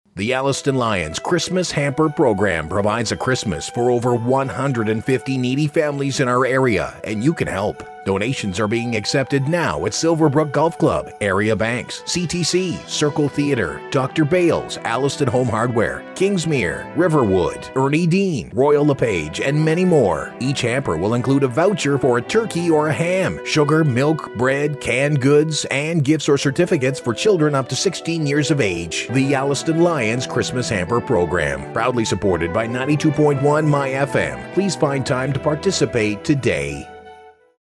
myFM Christmas Hamper Radio Ad